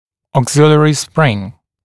[ɔːg’zɪlɪərɪ sprɪŋ][о:г’зилиэри сприн]вспомогательная пружина